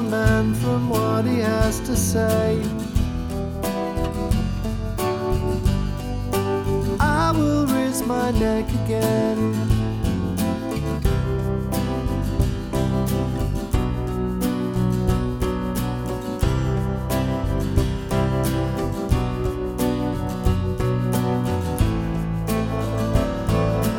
With Harmony Pop (1990s) 2:49 Buy £1.50